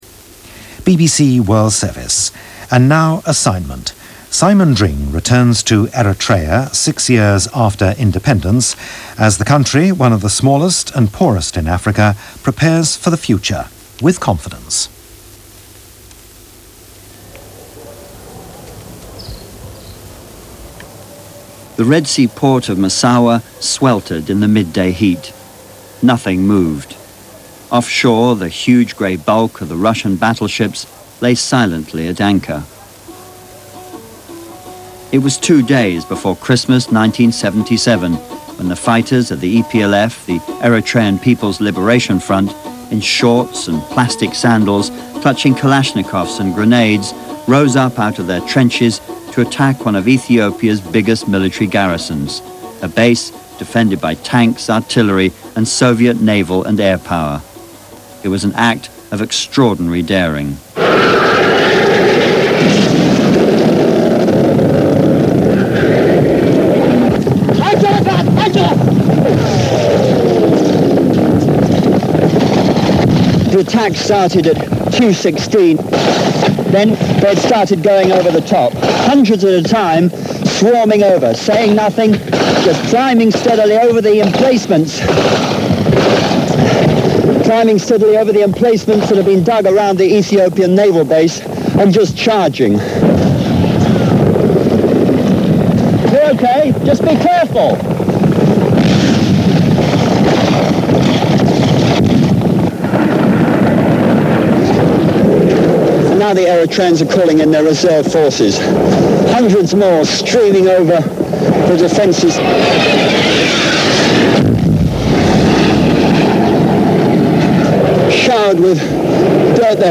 In August of 1997 the BBC World Service, as part of their weekly Assignment series, produced a documentary on the African nation of Eritrea, observing, some six years almost to the day Independence was declared and how the country was evolving in that time.